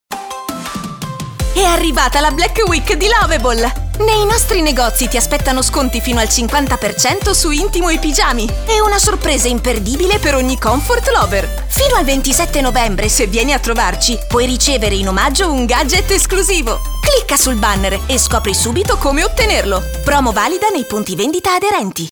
Demo
Spot